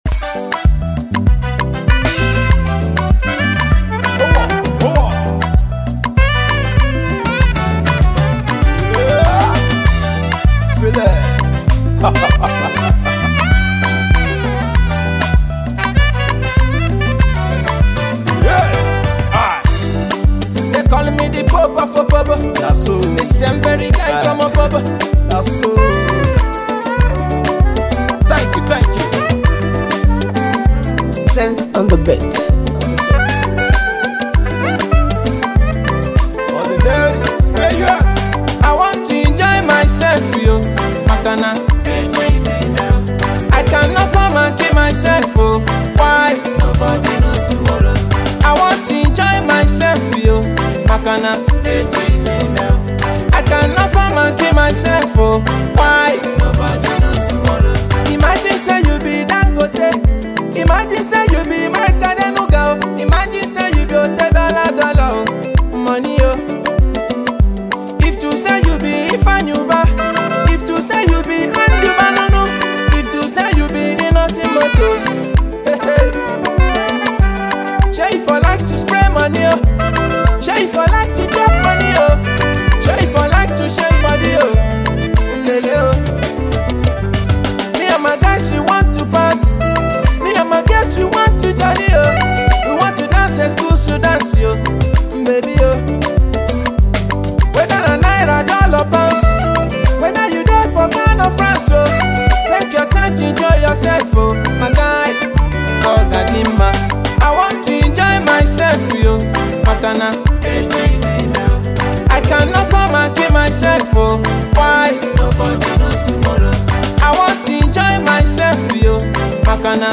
Afro-pop
Nigerian Ghana Hip Life and Afro Pop